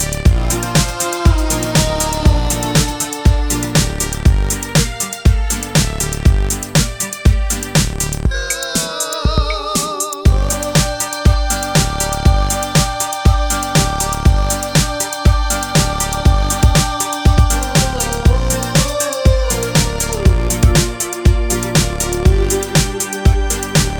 no talking BV Pop (2000s) 3:36 Buy £1.50